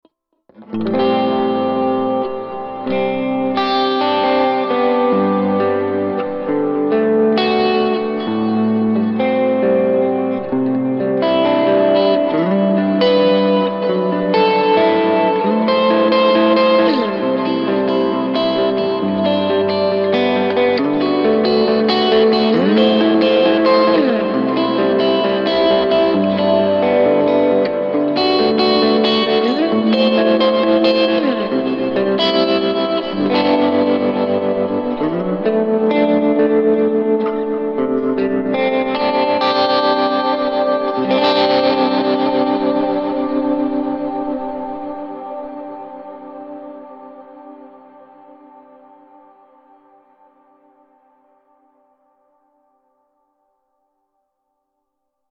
Create great sounding ambient music with just your guitar and Headrush with this pack from NVA.
VIBE - Toggle the vibrato
CHORUS - Toggle the chorus
TREMOLO - Toggle the amp tremolo
RAW AUDIO CLIPS ONLY, NO POST-PROCESSING EFFECTS